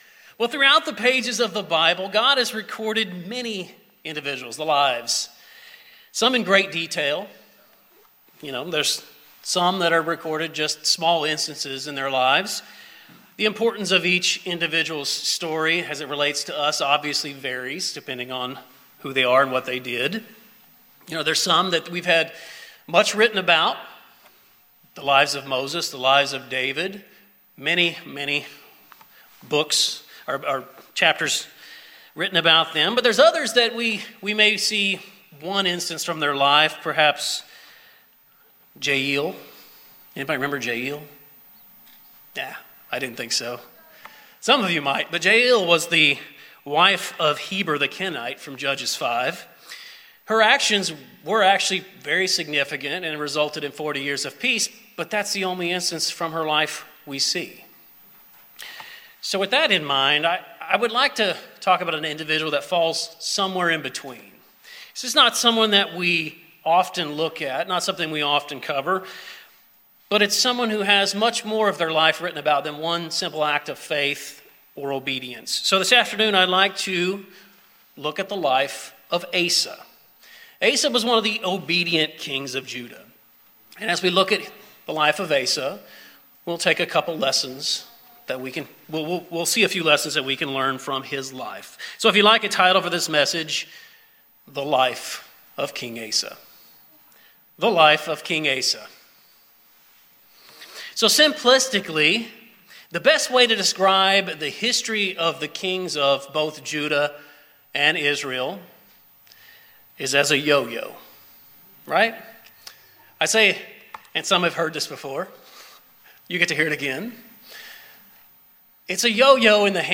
In this sermon we will examine the life of King Asa, the first "good" king of Judah, and review a few lessons we can learn from his life experience.